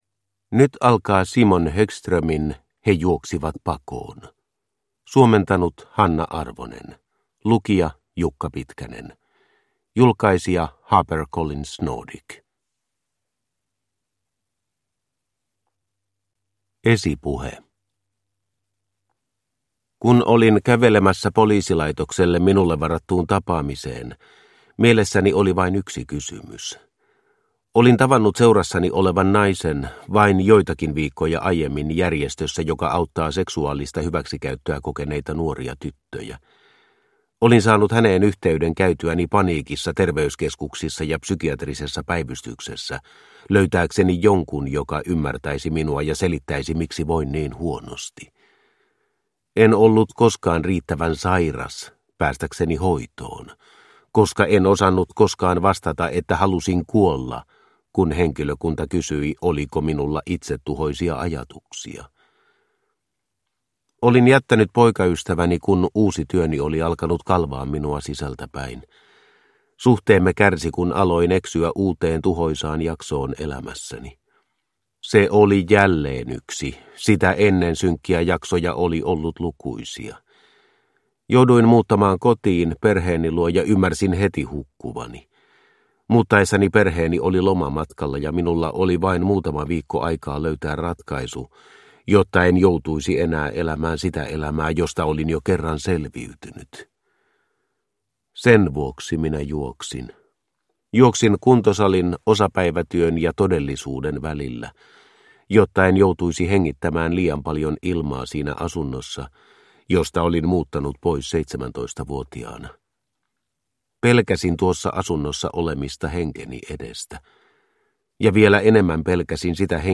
He juoksivat pakoon – Ljudbok – Laddas ner